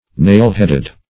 Nail-headed \Nail"-head`ed\, a.